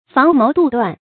房謀杜斷 注音： ㄈㄤˊ ㄇㄡˊ ㄉㄨˋ ㄉㄨㄢˋ 讀音讀法： 意思解釋： 房：唐朝宰相房玄齡；謀：計謀；杜：唐朝杜如晦；斷：決斷。